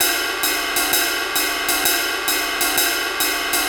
Jazz Swing #1 65 BPM.wav